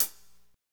Index of /90_sSampleCDs/Northstar - Drumscapes Roland/DRM_Pop_Country/HAT_P_C Hats x
HAT P C C03R.wav